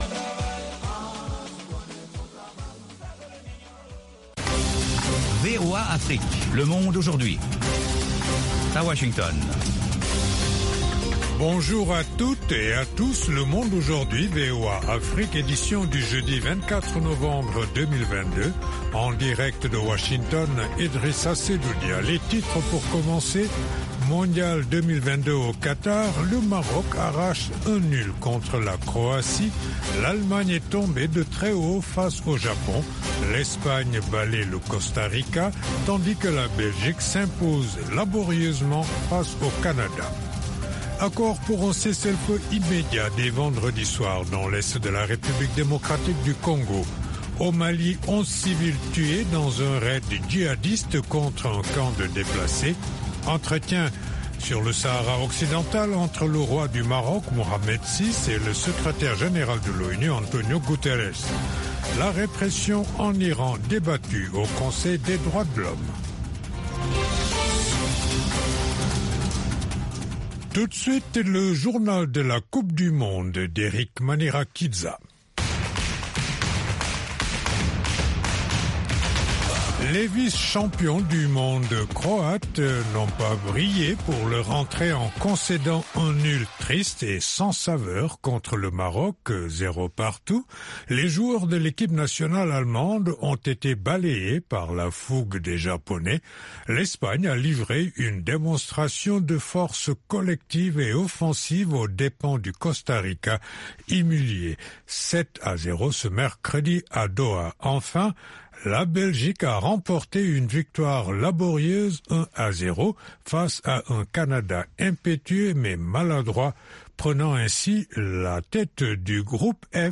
Le programme phare du service francophone dure 30 minutes sur les informations de dernières minutes, des reportages de nos correspondants, des interviews et analyses sur la politique, l’économie, les phénomènes de société et sur la société civile.